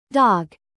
dog.mp3